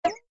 MG_maze_pickup.ogg